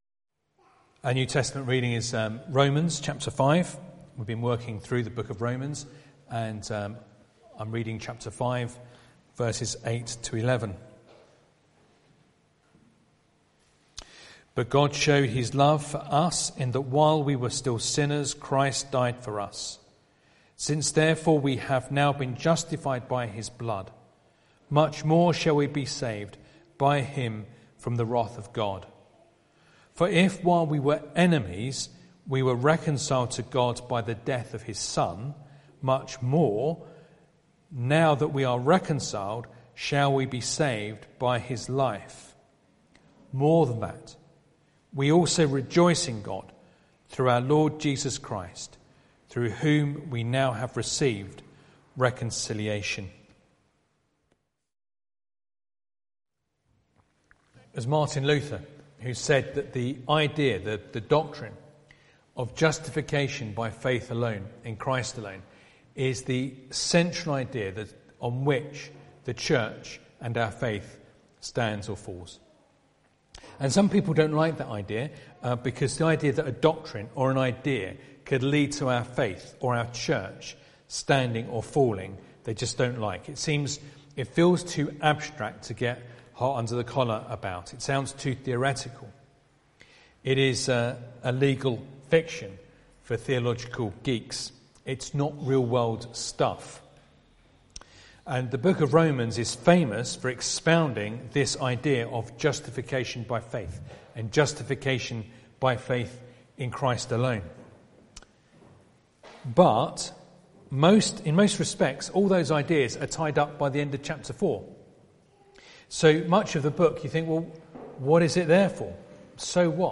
Sunday Evening Reading and Sermon